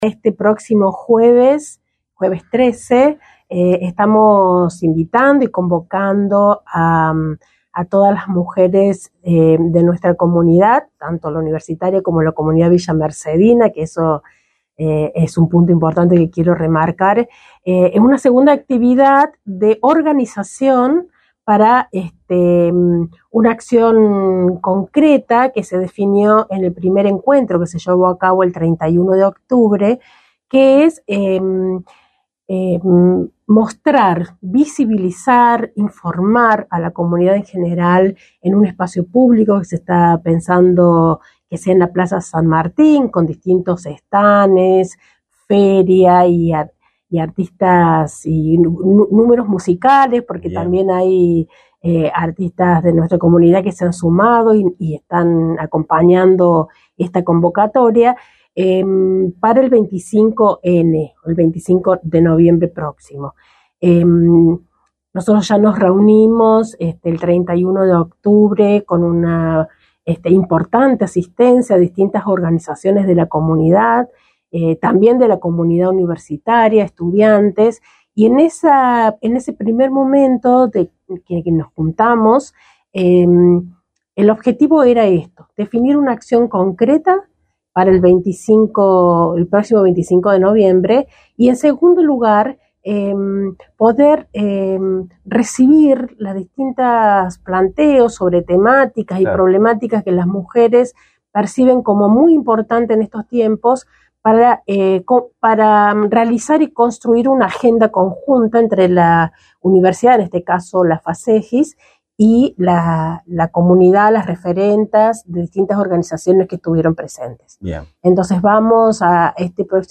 Durante una entrevista en Radio UNSL 97.5 FM